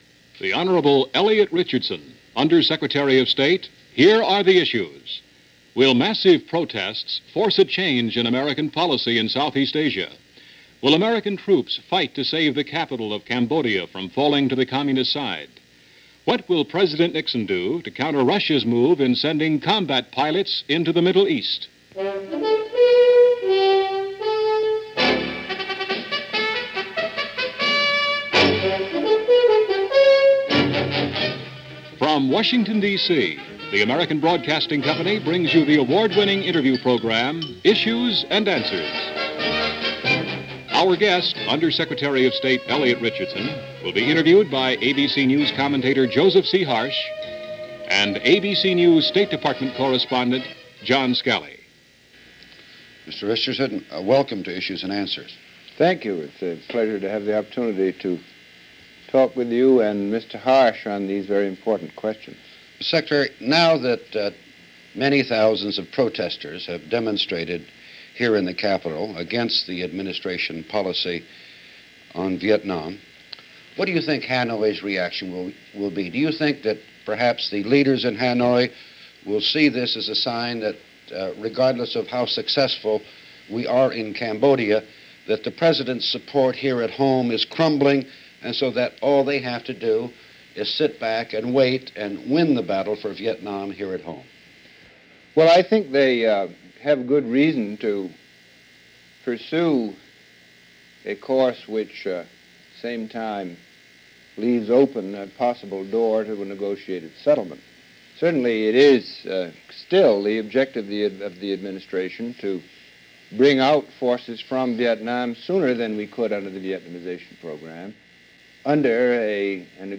The Secretary Of State is grilled over the Kent State protests and our policy in Southeast Asia.